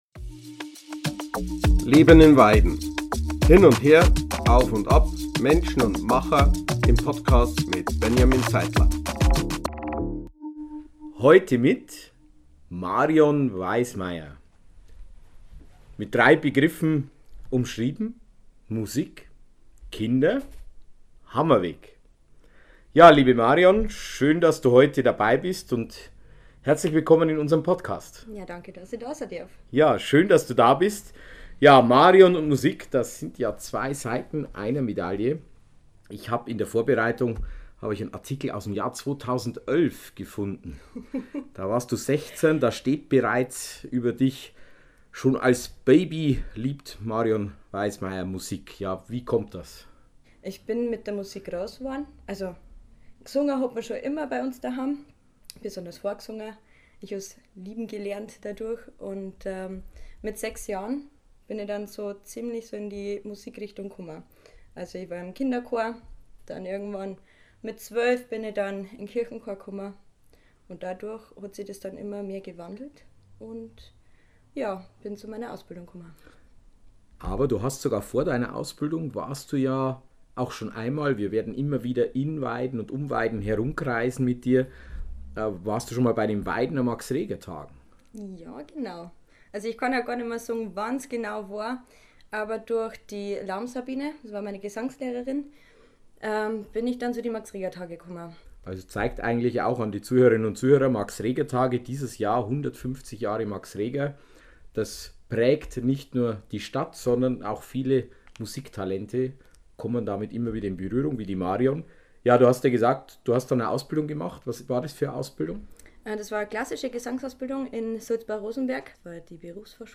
Auch in diesem Podcast zeigt sie ihr Können.